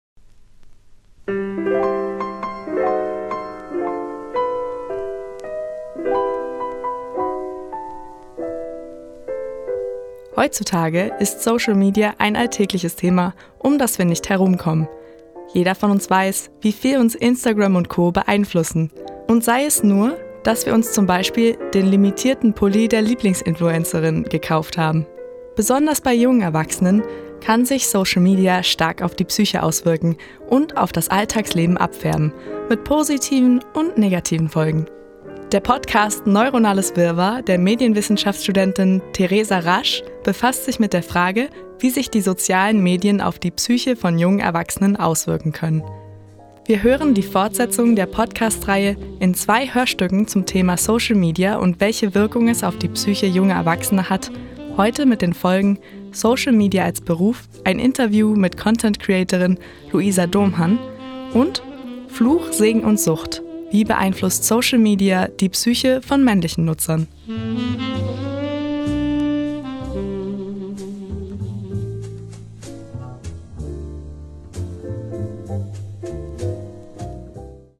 Vier Student*innen teilen ihre Erfahrungen mit Social-Media-Plattformen wie Instagram.